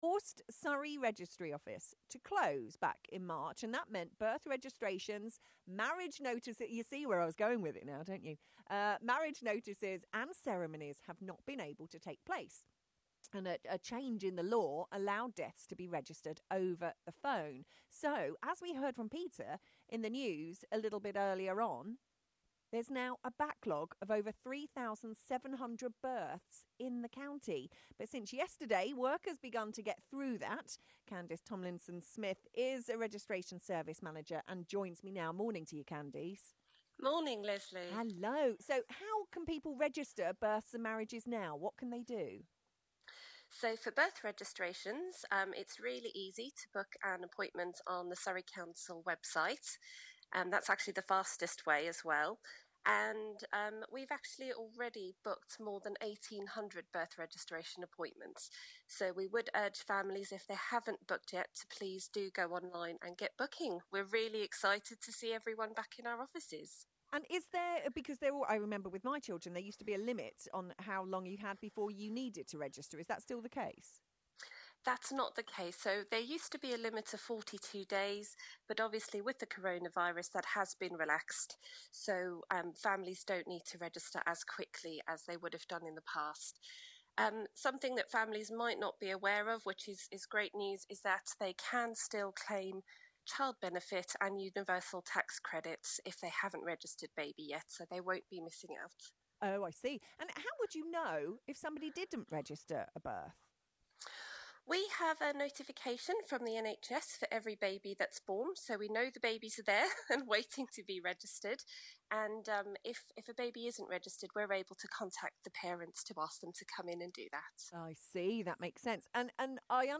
AUDIO: BBC Surrey interview on work that’s begun to register the backlog of over 3,700 births during coronavirus